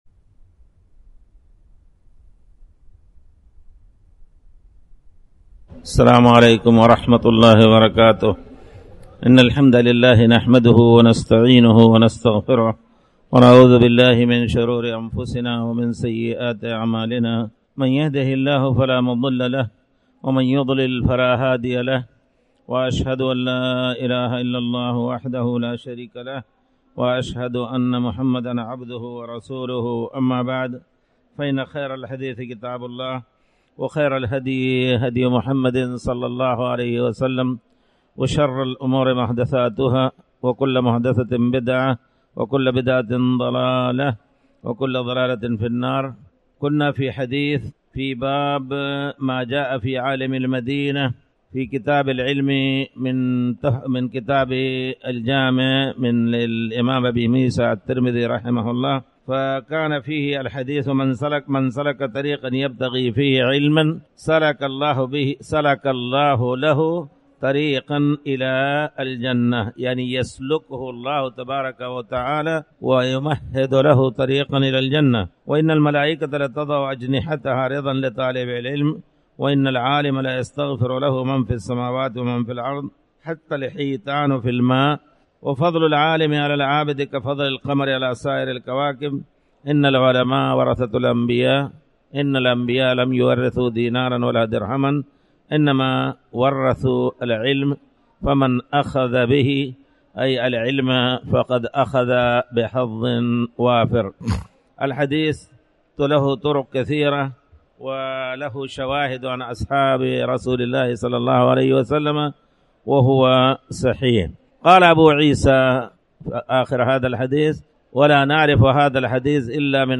تاريخ النشر ٣ شعبان ١٤٣٩ هـ المكان: المسجد الحرام الشيخ